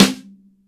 MIX SN RIM02.wav